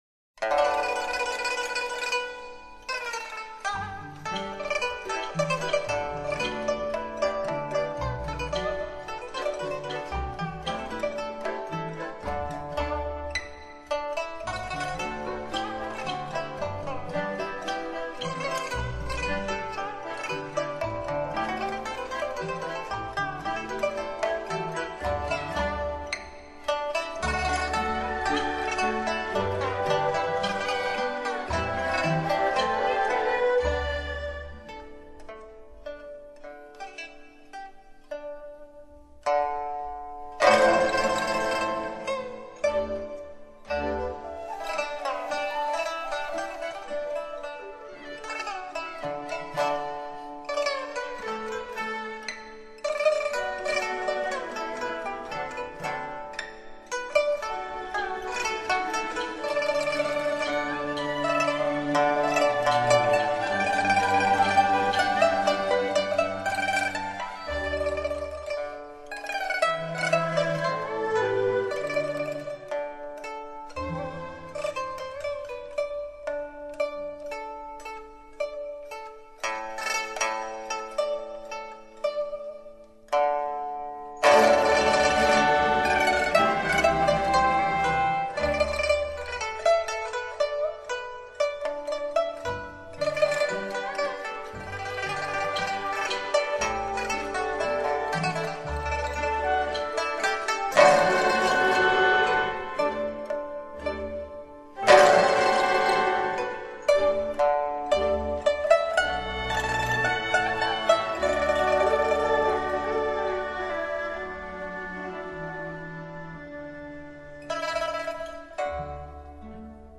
音乐类型：中国民乐
乐曲形象生动、充满热情，
富有生气，优美动听。